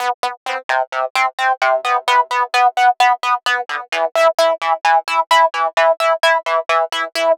Filter Seq C 130.wav